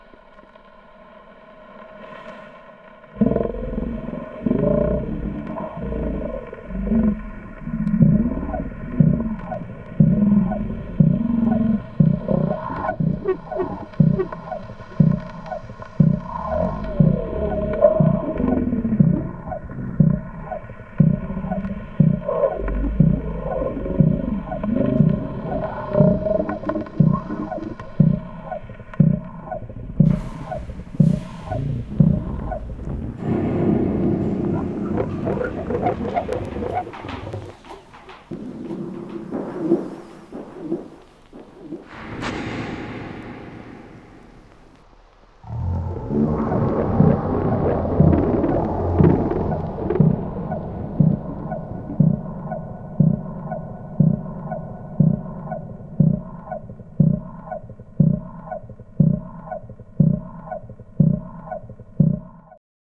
060 Gas Fire Monster.wav